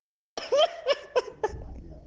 Play خنده داف - SoundBoardGuy